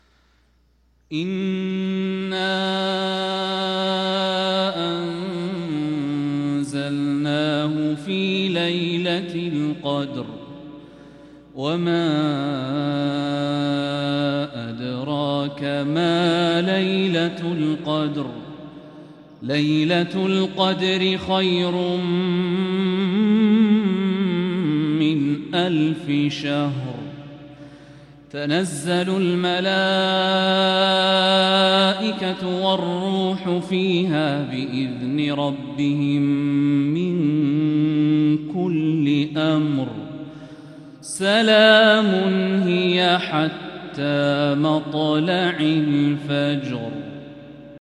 سورة القدر | فروض ربيع الأخر 1446هـ > السور المكتملة للشيخ الوليد الشمسان من الحرم المكي 🕋 > السور المكتملة 🕋 > المزيد - تلاوات الحرمين